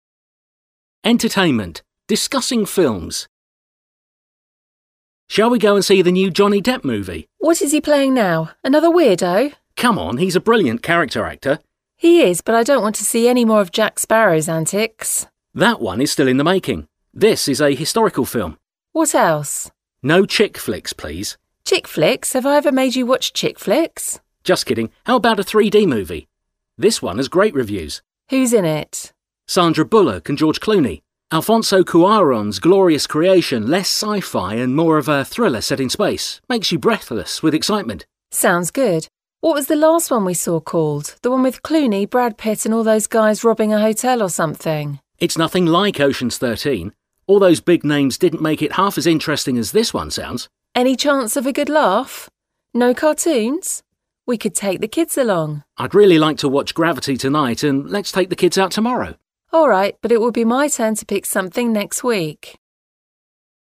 HASZNOS PÁRBESZÉD: Discussing films – Beszélgetés filmekről